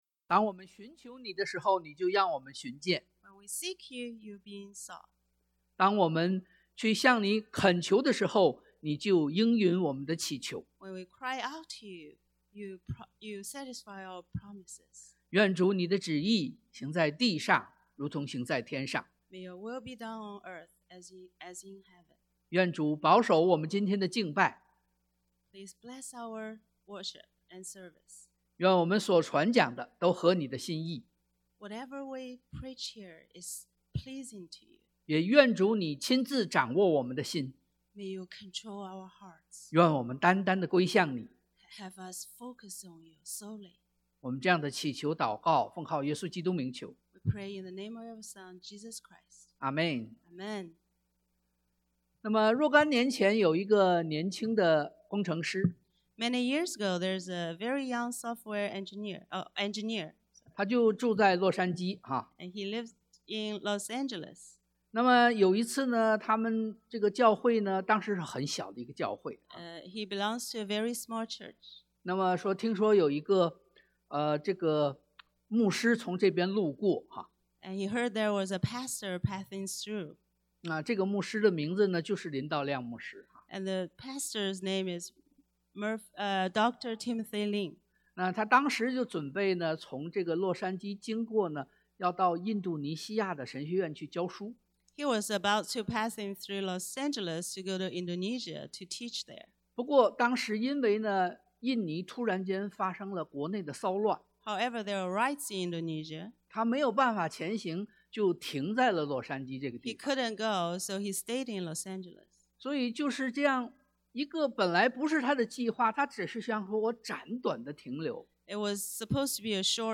太Mat 16:13-20 Service Type: Sunday AM Jesus guided his disciples 耶穌引導門徒 Experience the confession of Christ 體驗承認基督 Release power from the above 能力釋放 « 2024-03-10 Did No One Condemn You?